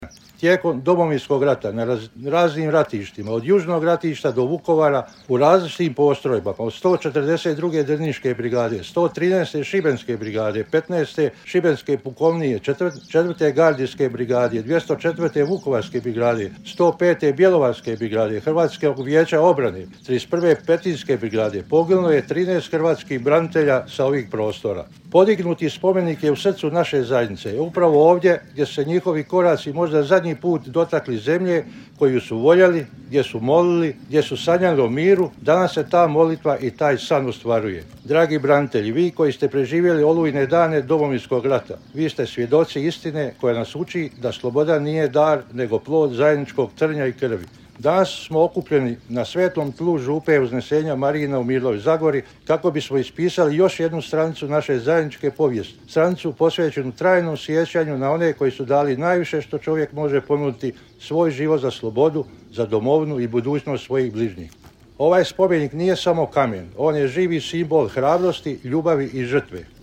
Pokraj crkve Uznesenja Marijina u Mirlović Zagori svečano je jučer poslijepodne otkriven spomenik posvećen poginulim hrvatskim braniteljima s tog područja.
Trinaest branitelja sa područja Zagore poginuli su na ratištima od Vukovara do Dubrovnika, podsjetio je načelnik općine Unešić Živko Bulat: